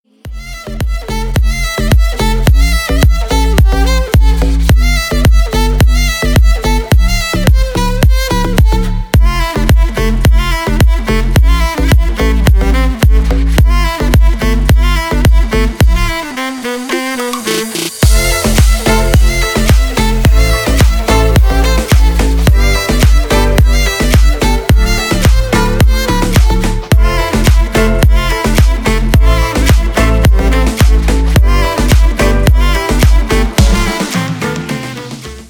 • Качество: 320, Stereo
ритмичные
deep house
Electronic
качающие
Саксофон
духовые
Стиль: deep house